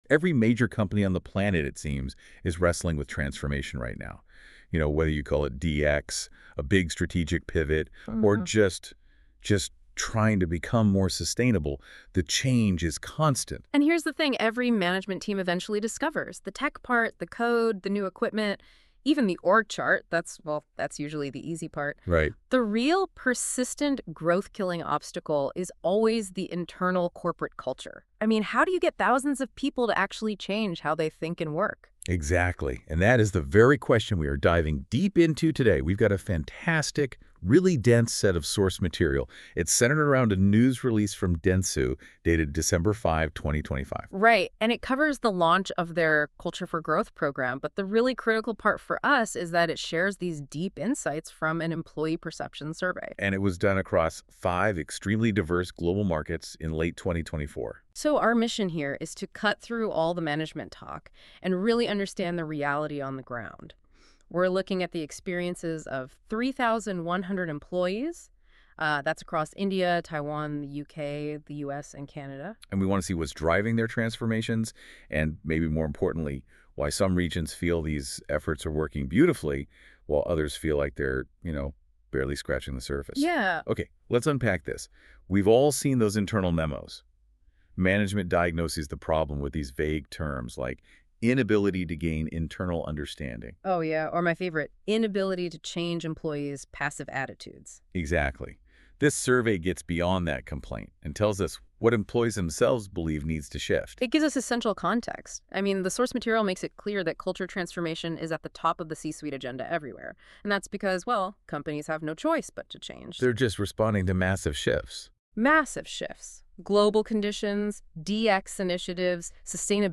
You can listen to a NotebookLM audio overview synopsizing the study here . advertisement advertisement